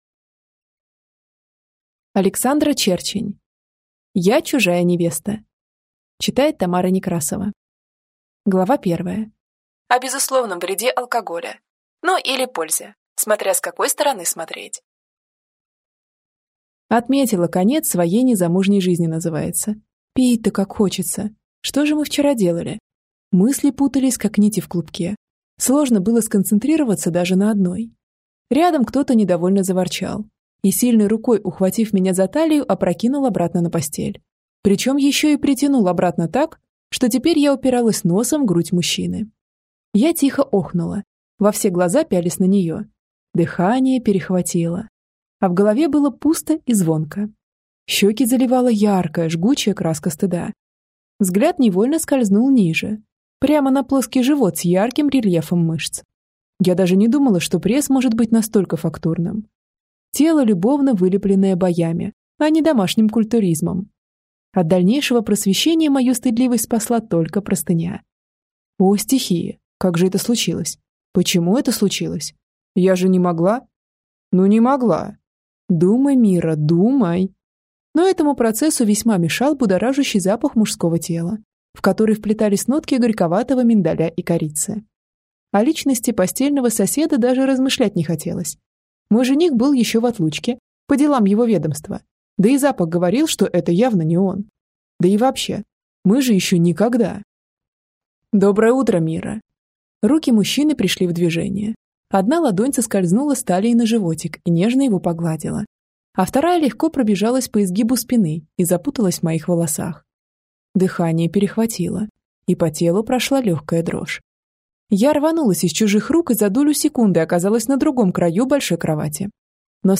Аудиокнига Я чужая невеста - купить, скачать и слушать онлайн | КнигоПоиск
Аудиокнига «Я чужая невеста» в интернет-магазине КнигоПоиск ✅ в аудиоформате ✅ Скачать Я чужая невеста в mp3 или слушать онлайн